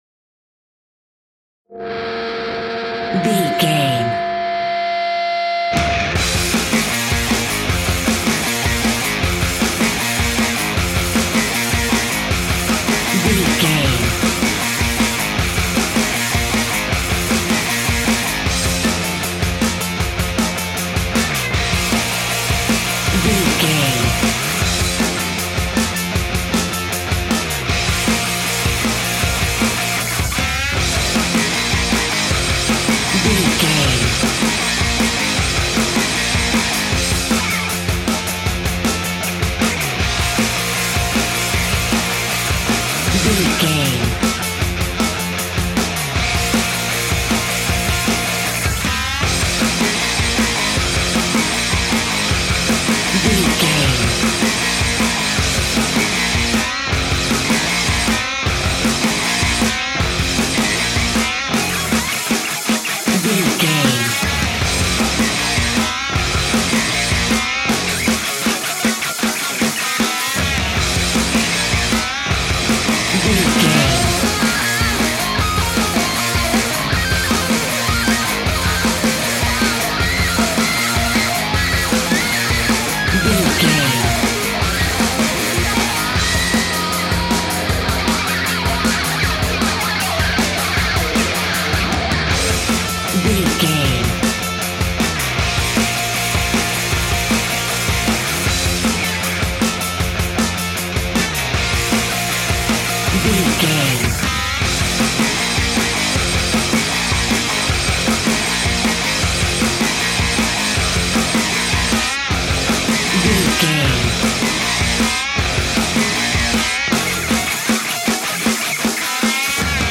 Epic / Action
Aeolian/Minor
hard rock
heavy metal
blues rock
distortion
rock guitars
Rock Bass
Rock Drums
heavy drums
distorted guitars
hammond organ